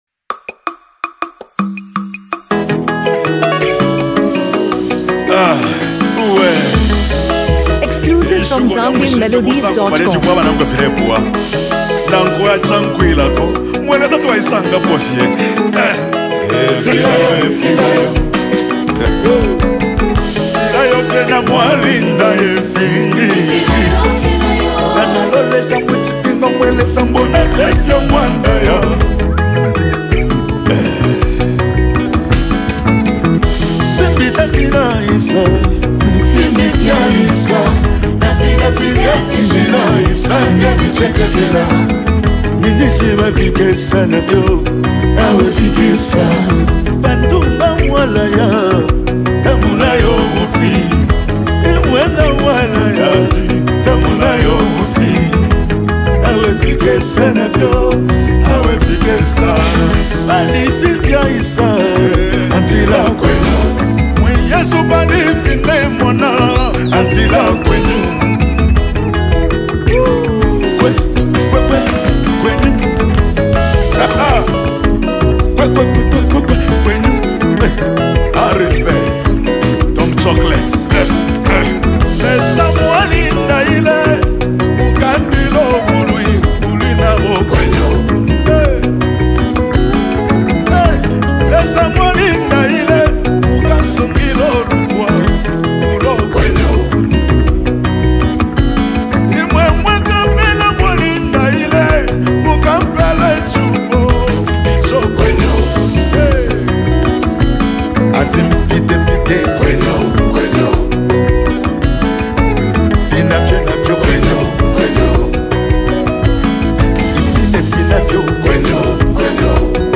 Zambian gospel
spiritually charged anthem
commanding vocals